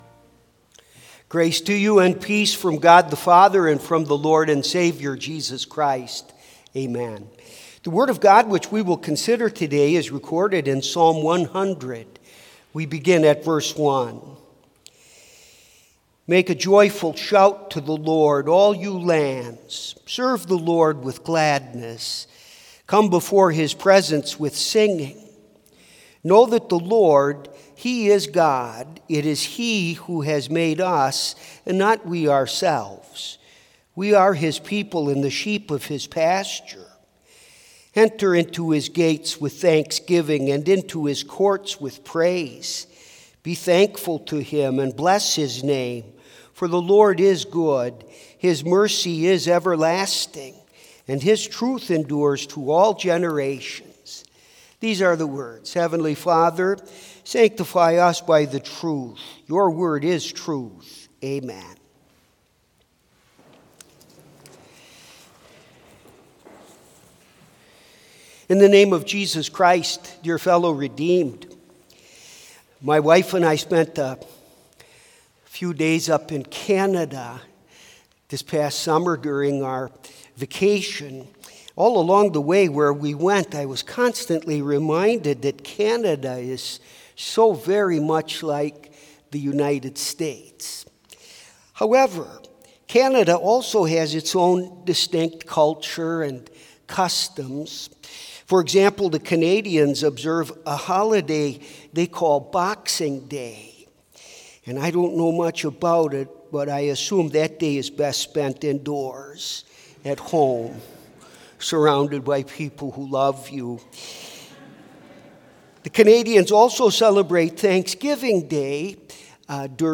Complete service audio for Chapel - September 22, 2022